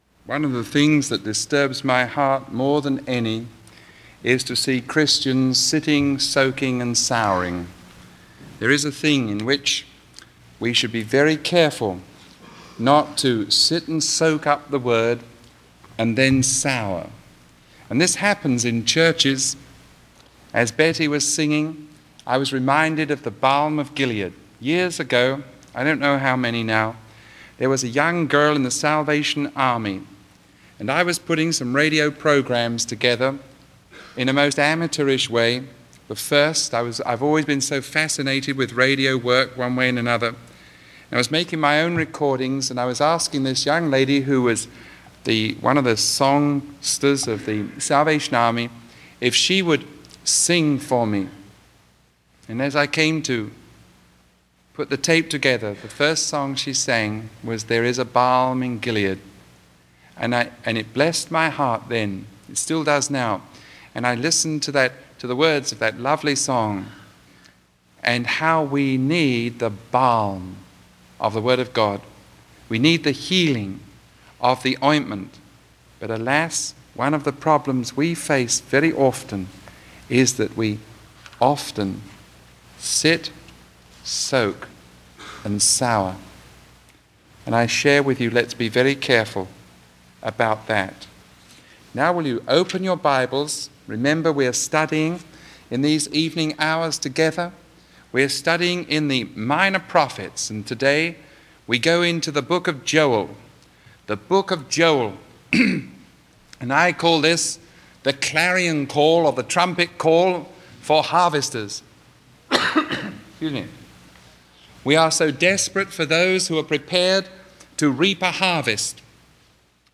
Sermon 0009A recorded on December 3